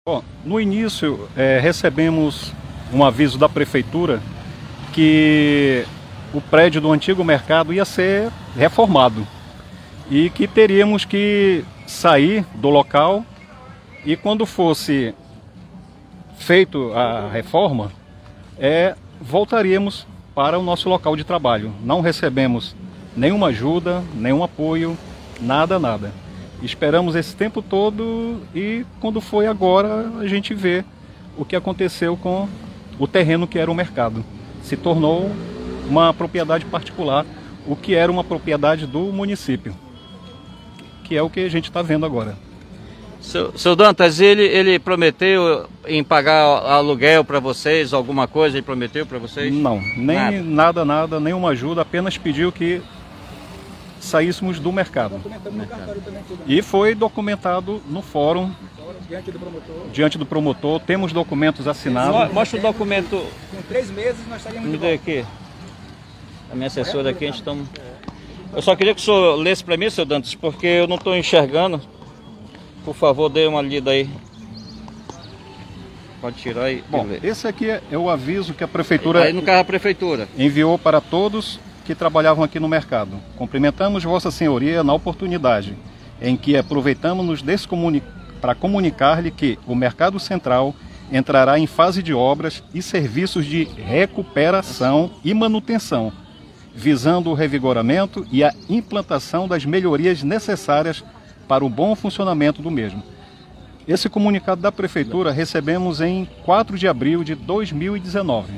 Áudio do vídeo gravado pelo Vereador João Doza